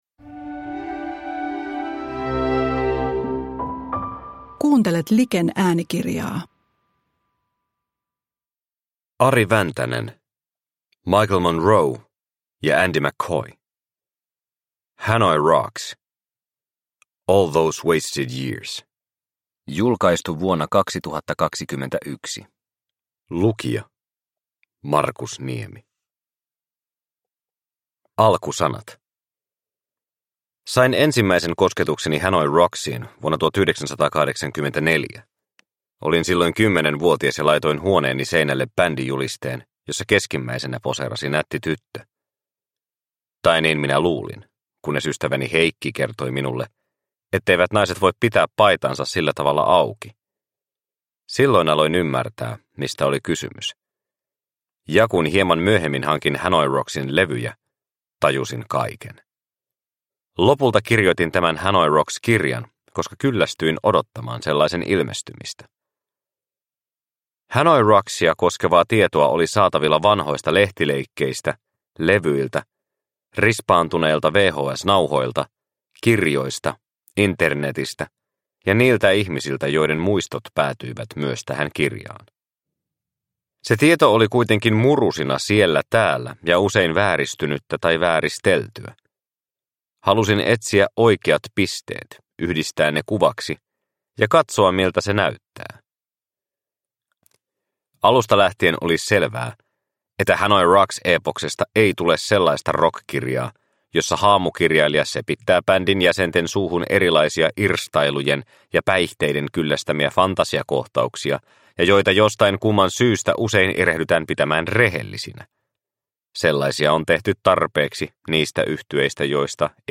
Hanoi Rocks - All Those Wasted Years – Ljudbok – Laddas ner